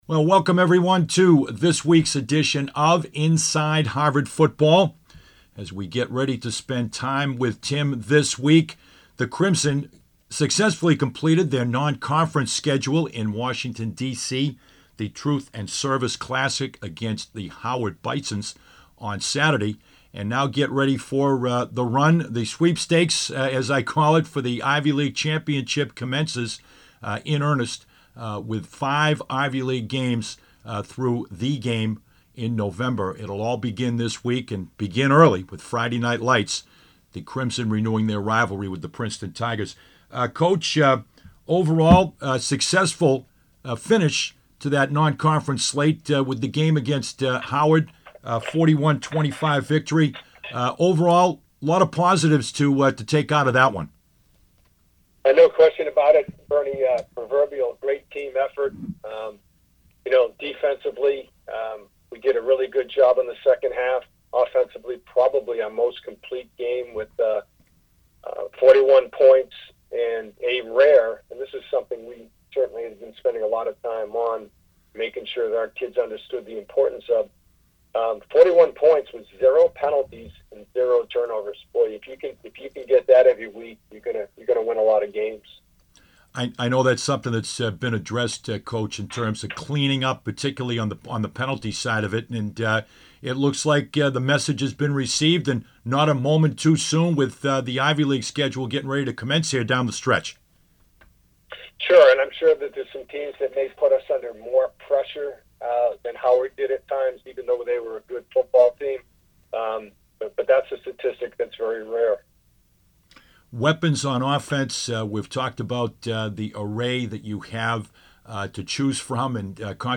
Full Interview